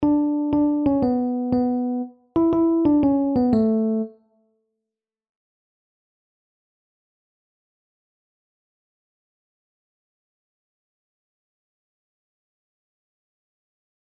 Kleine terts